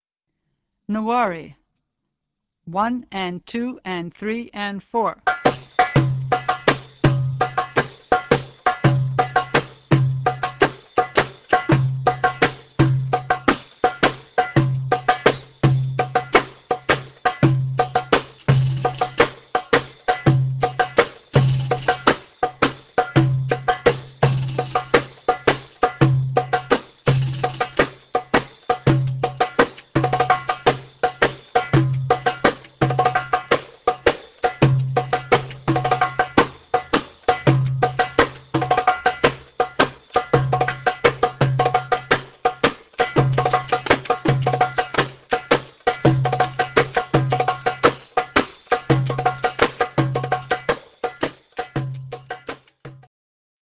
doumbec and tambourine
Nawwari